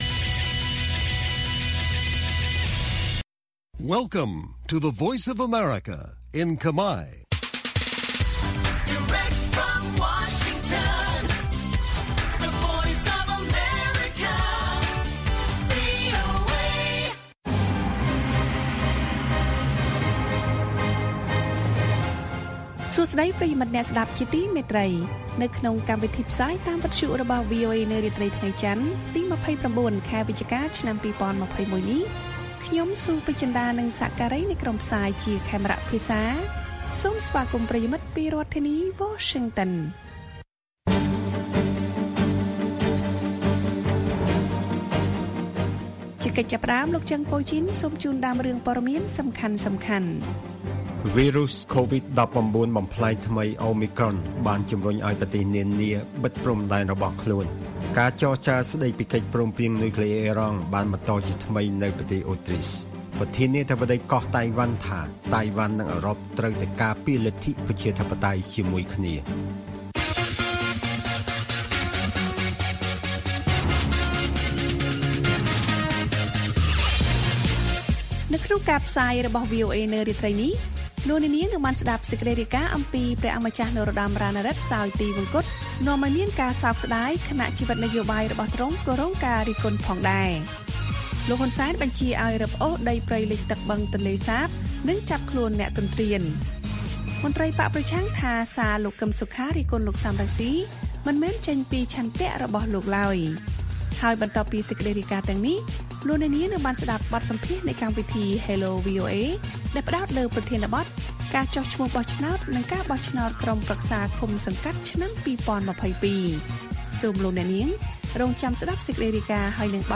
ព័ត៌មានពេលរាត្រី៖ ២៩ វិច្ឆិកា ២០២១